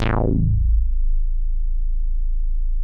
MICROMOOG A1.wav